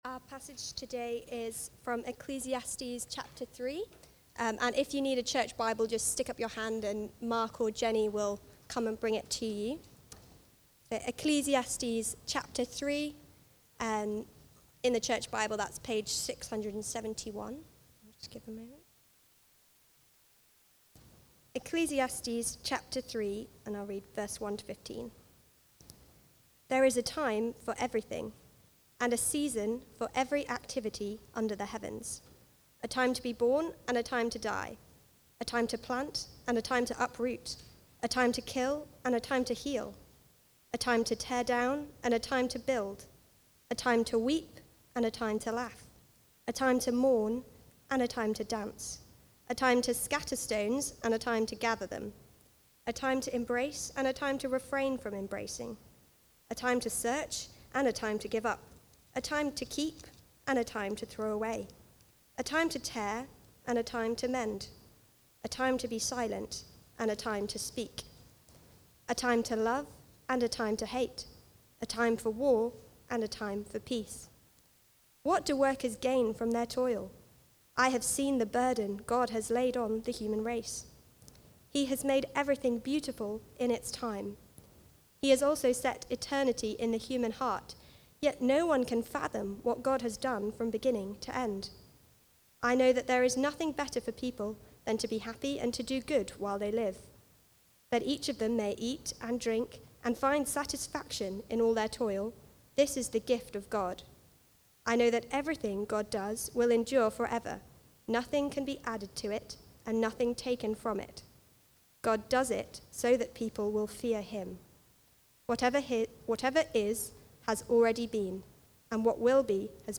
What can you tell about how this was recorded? A Time For Everything (Ecclesiastes 3:1-15) from the series Chasing After the Wind. Recorded at Woodstock Road Baptist Church on 21 September 2025.